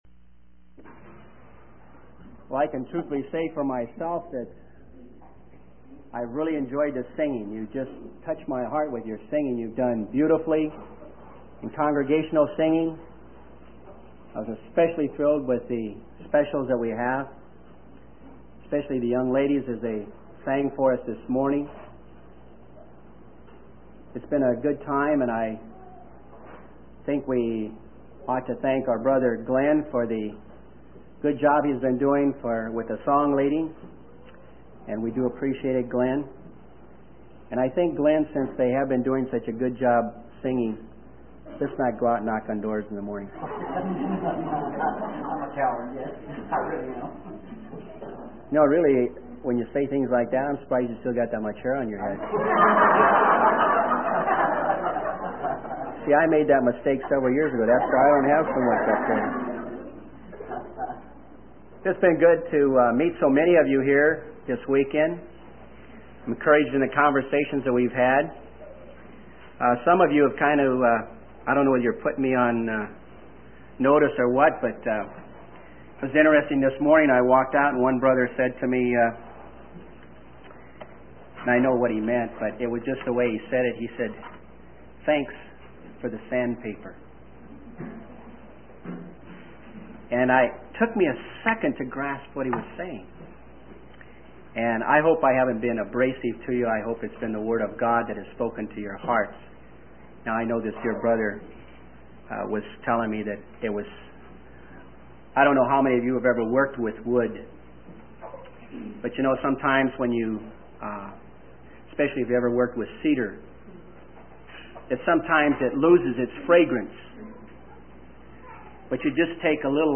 In this sermon, the preacher focuses on the life of Enoch, a man mentioned in both the Old and New Testaments. The preacher highlights the importance of not placing value on earthly treasures that can be destroyed or stolen, but instead emphasizes the need to lay up treasures in heaven.